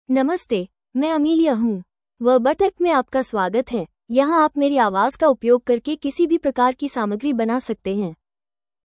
Amelia — Female Hindi (India) AI Voice | TTS, Voice Cloning & Video | Verbatik AI
Amelia is a female AI voice for Hindi (India).
Voice sample
Amelia delivers clear pronunciation with authentic India Hindi intonation, making your content sound professionally produced.